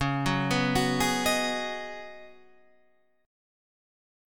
Db9sus4 chord